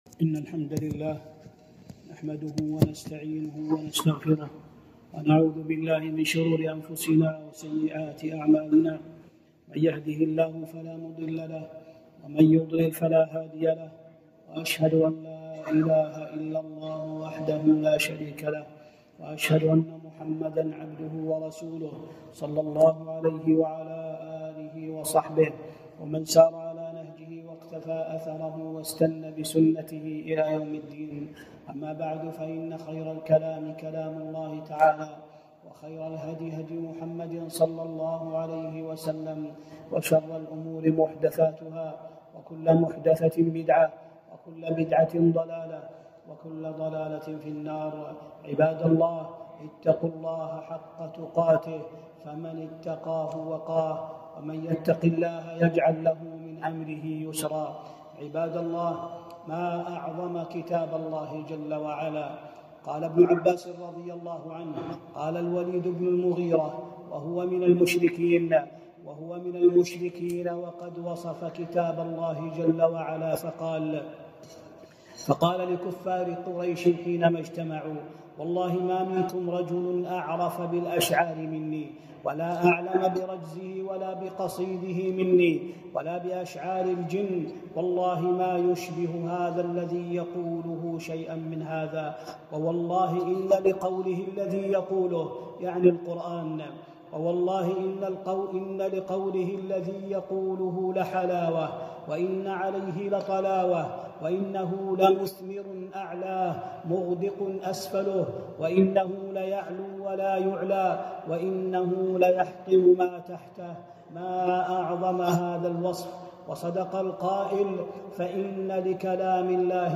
خطبة - فضل سماع القرآن